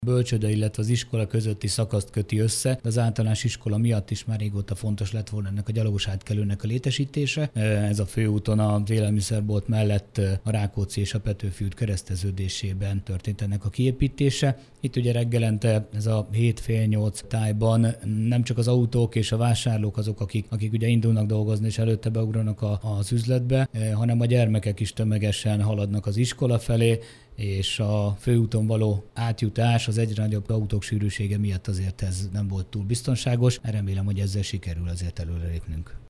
Nagy János polgármestert hallják: